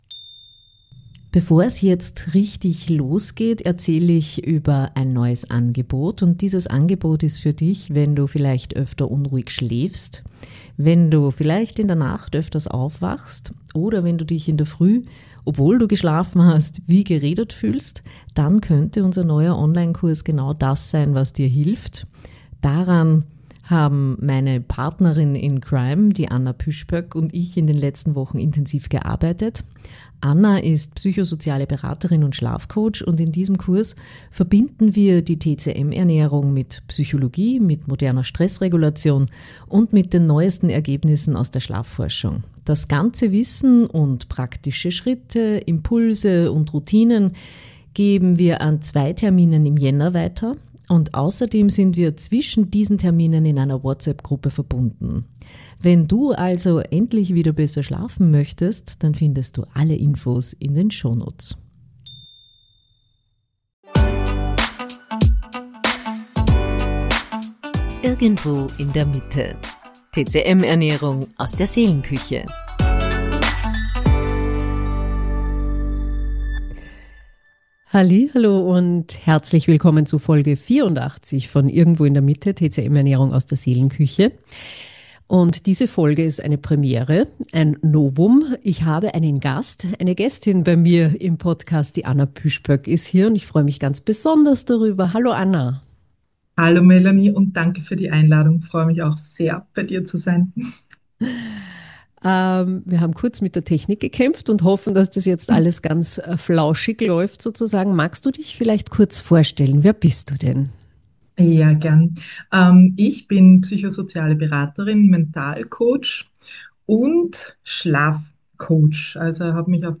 In dieser Folge gibt es eine Premiere: ich habe eine Gesprächspartnerin!